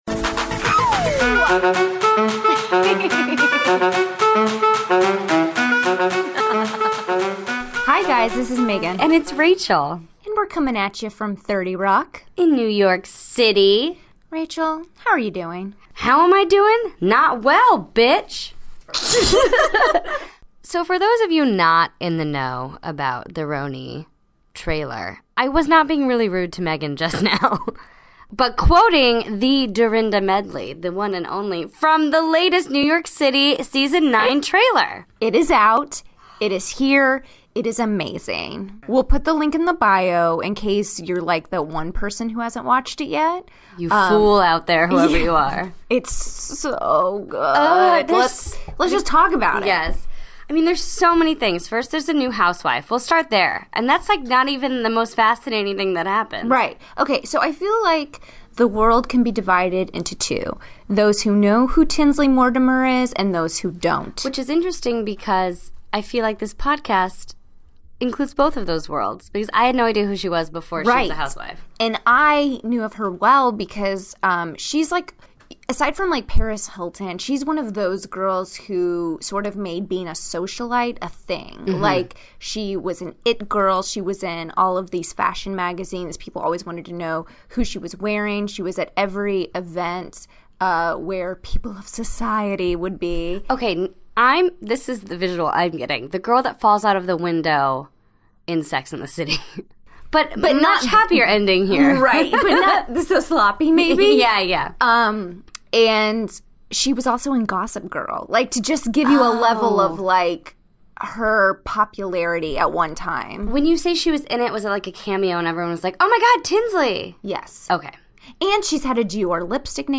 "You're Not That Famous" (Our Interview with Jax Taylor)